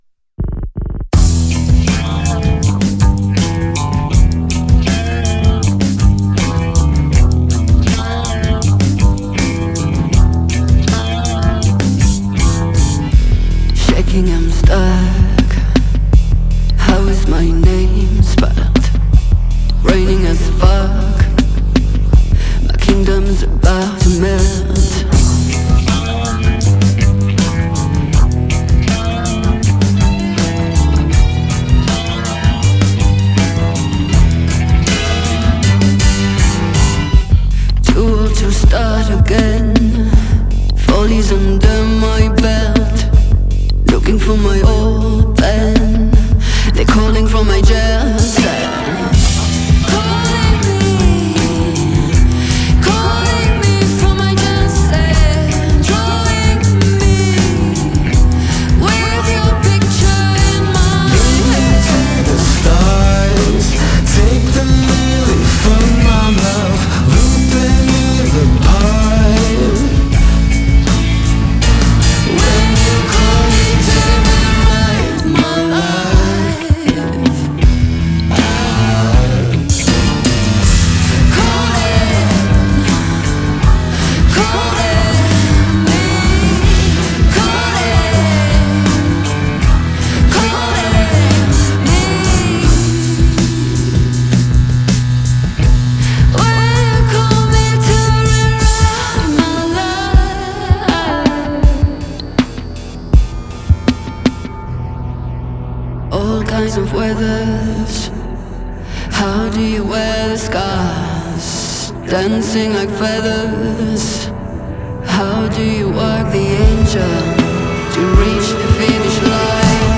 es en realidad un duo creado por la cantante francesa